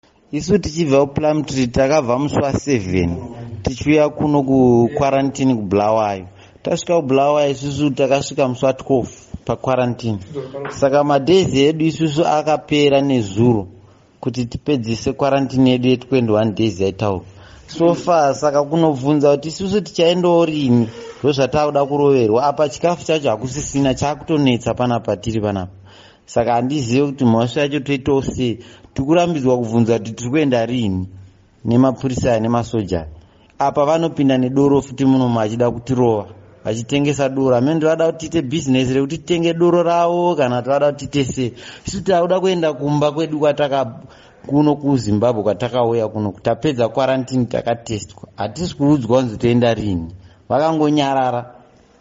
Chizvarwa cheZimbabwe Chichitaura